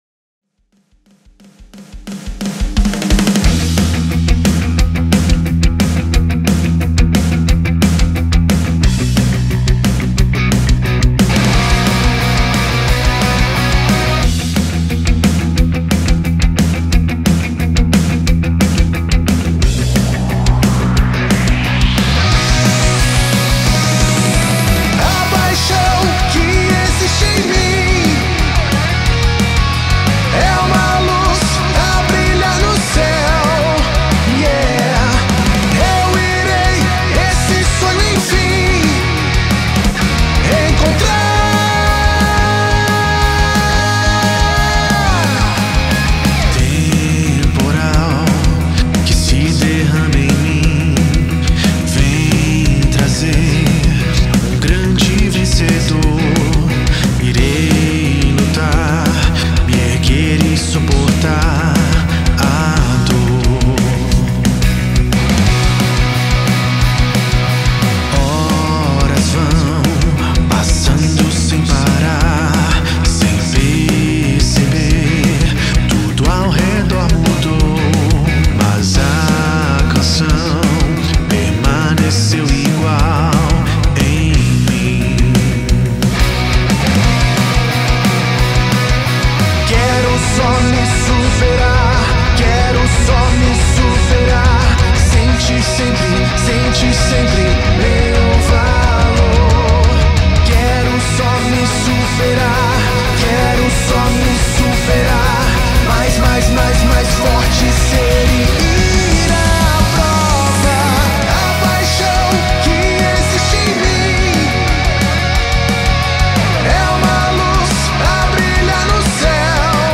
2025-02-23 15:55:35 Gênero: Rap Views